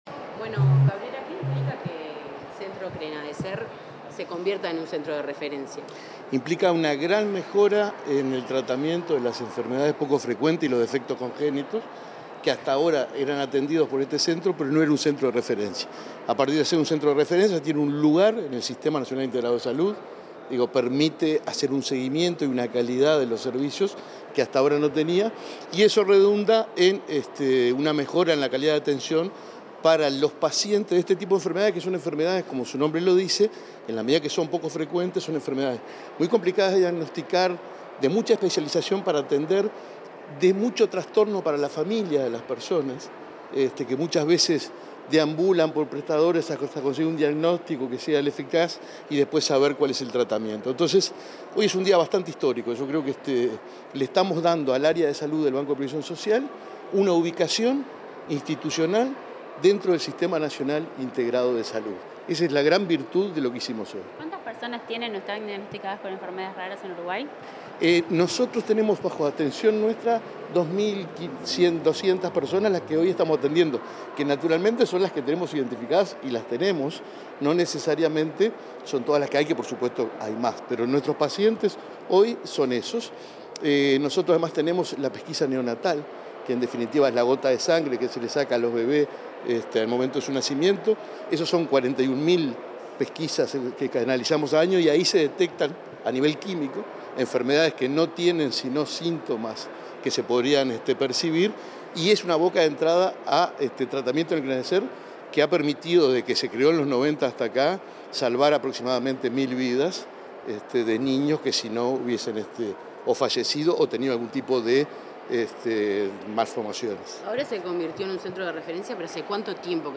Declaraciones a la prensa del presidente del BPS, Alfredo Cabrera
Declaraciones a la prensa del presidente del BPS, Alfredo Cabrera 19/11/2024 Compartir Facebook X Copiar enlace WhatsApp LinkedIn El Banco de Previsión Social (BPS) y el Fondo Nacional de Recursos firmaron, este 19 de noviembre, el convenio y la presentación del Centro Nacional de Referencia en Defectos Congénitos y Enfermedades Raras. Tras el evento, el presidente del BPS, Alfredo Cabrera, realizó declaraciones a la prensa.